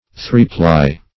Three-ply \Three"-ply`\, a.